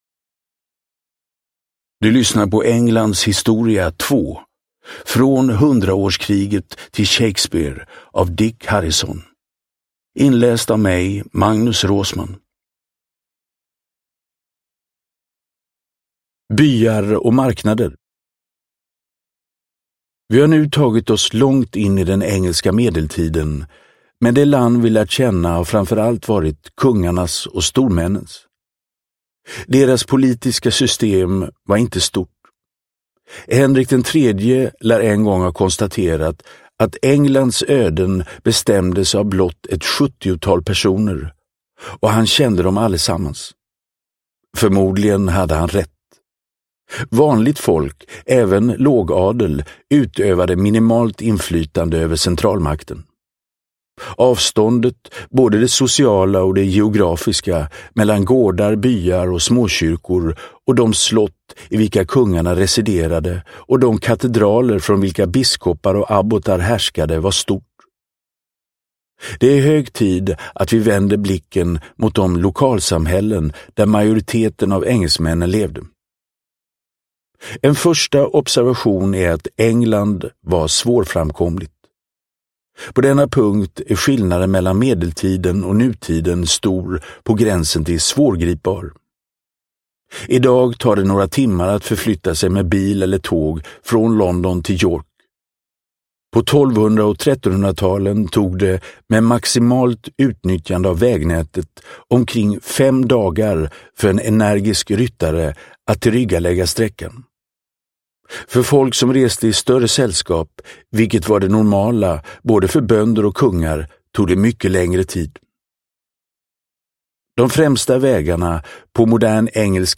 Englands historia, 2. Från hundraårskriget till Shakespeare – Ljudbok